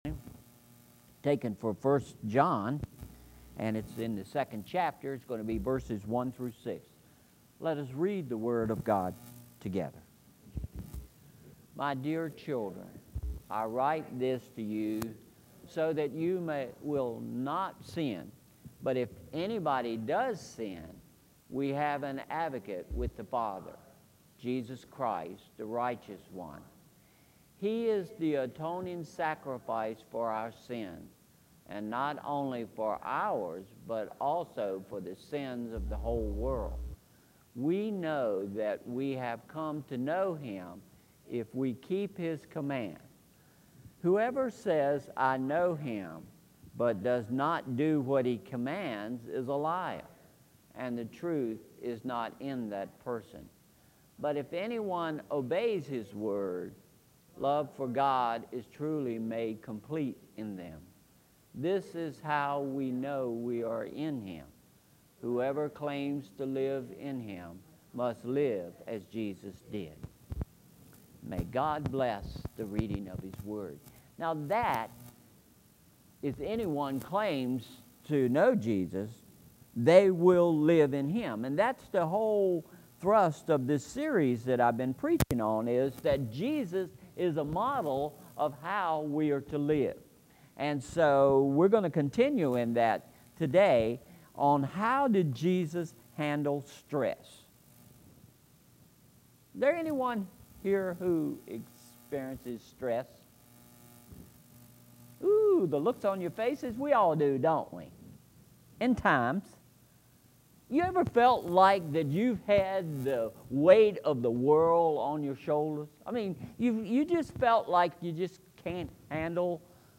Sermon Series: The Jesus Model – Part 3